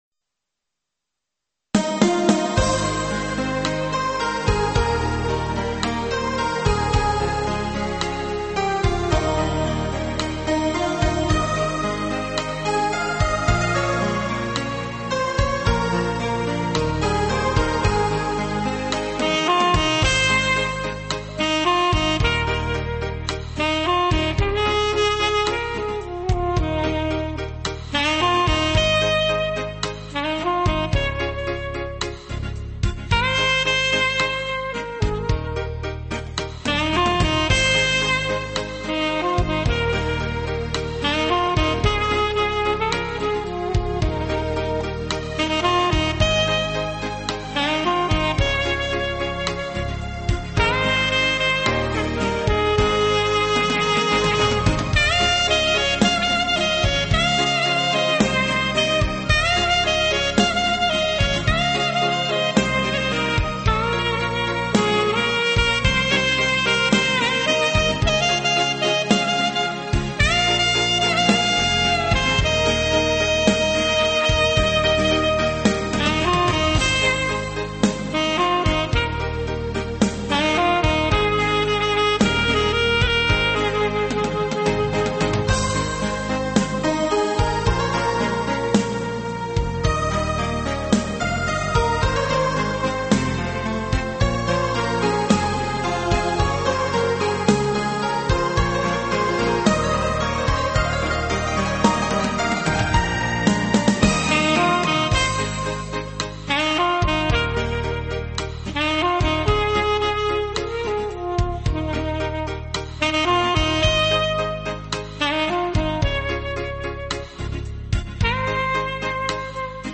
纯音乐
悠扬如诗清新的乐曲